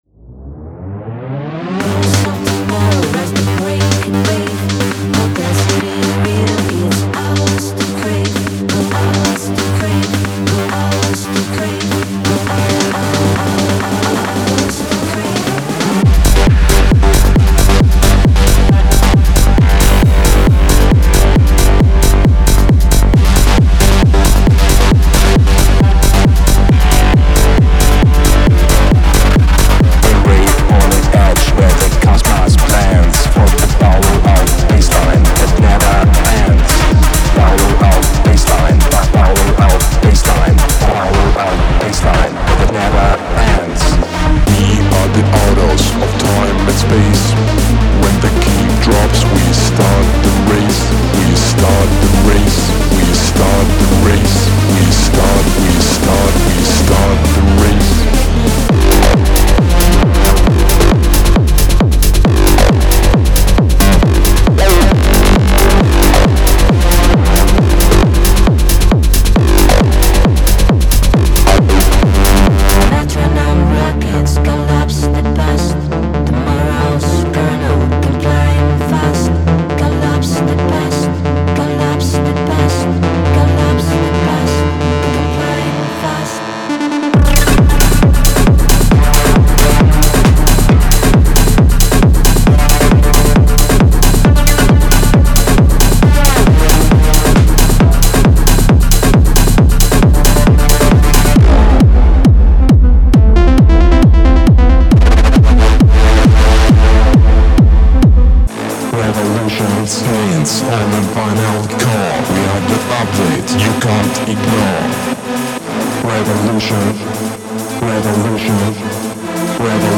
テクノ系サンプルパックをご紹介いたします。
ピークタイムのテクノデストラクションのための究極のツールキット
力強いキック、鋭いシンセ、地を揺るがすベースライン、そして刺激的なエフェクトが満載です。
・135BPM